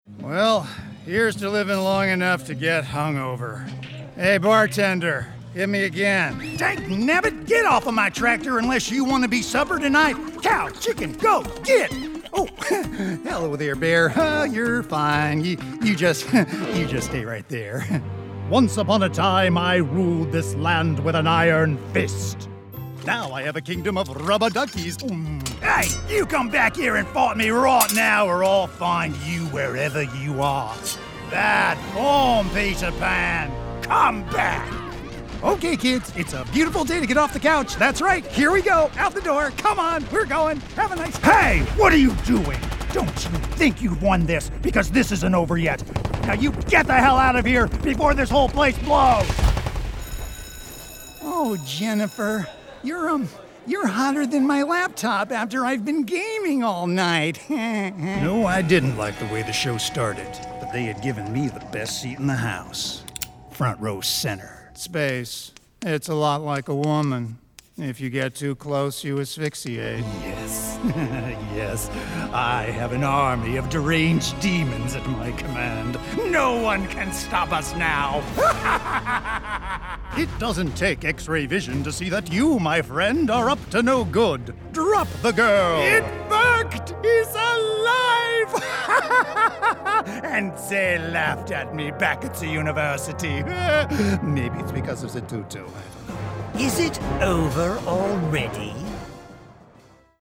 Animation Demo
Character Voice